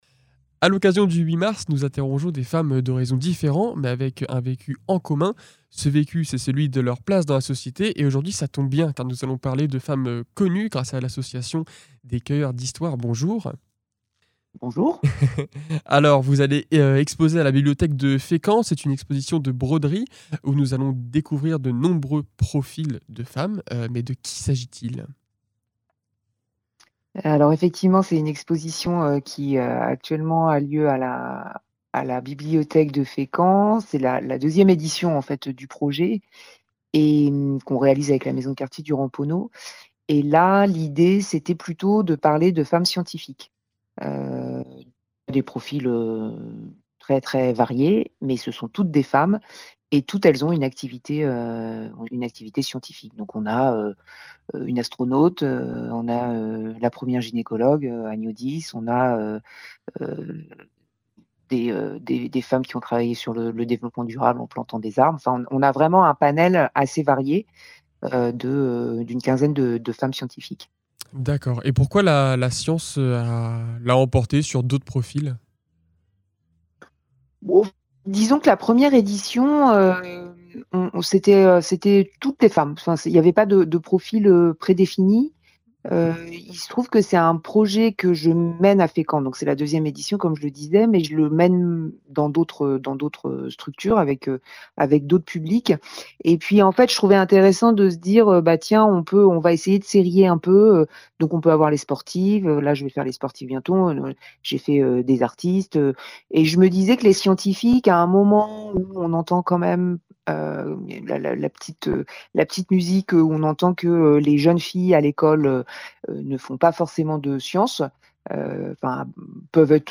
À l’occasion de la Journée des droits des femmes, Radar souhaite créer une émission spéciale pour interroger différentes femmes sur leur métier, en mêlant actualité et histoire personnelle.